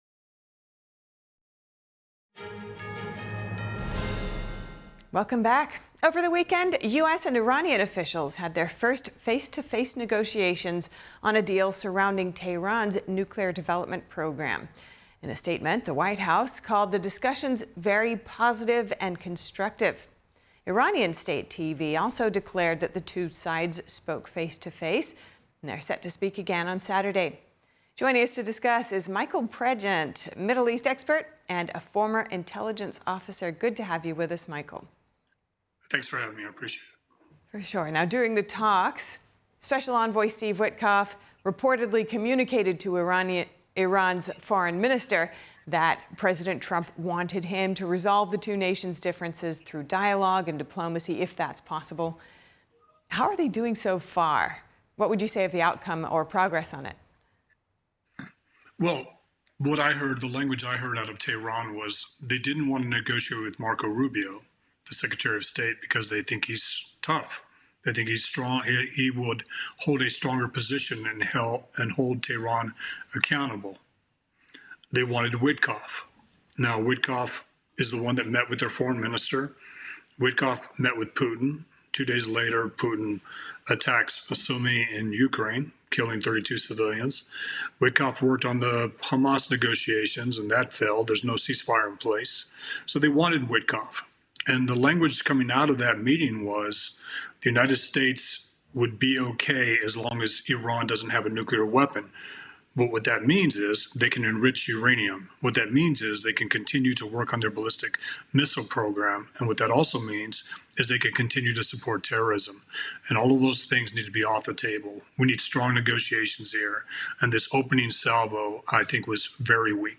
NTD-News-Today-Full-Broadcast-April-14-audio-converted.mp3